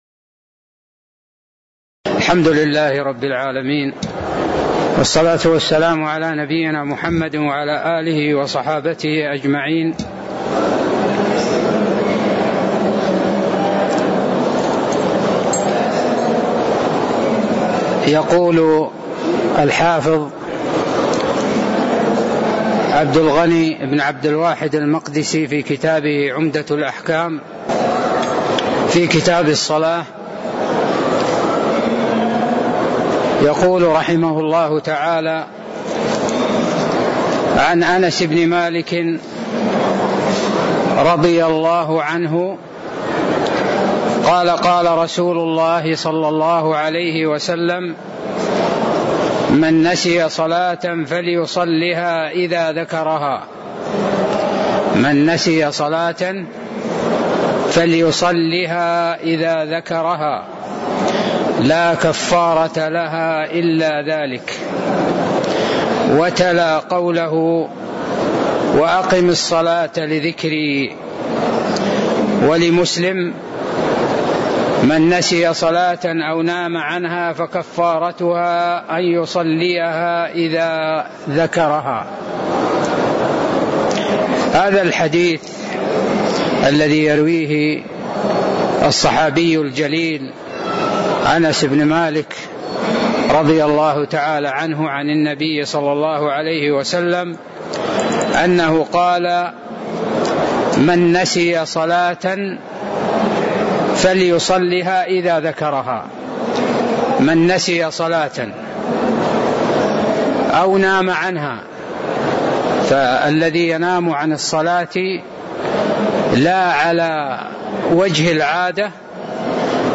تاريخ النشر ٣ صفر ١٤٣٦ هـ المكان: المسجد النبوي الشيخ